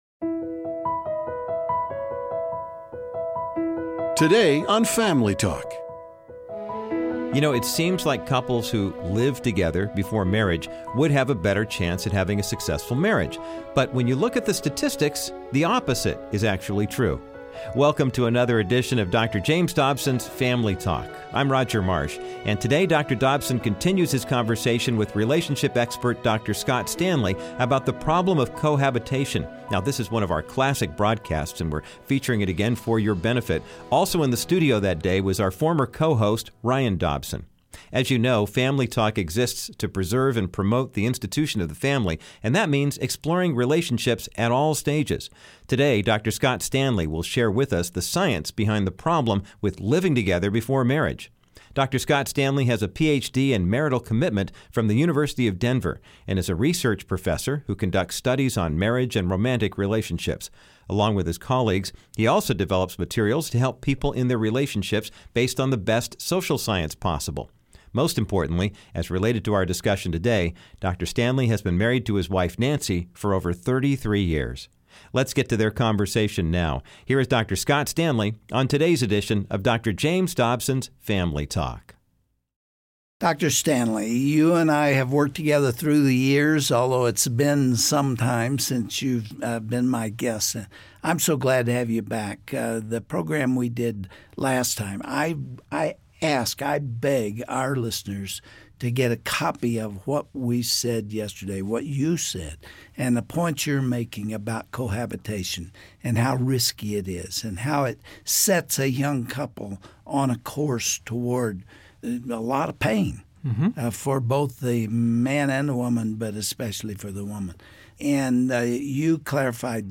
Many people think that by living together before marriage they are setting themselves up for success, but actually the opposite is true. Dr. James Dobson interviews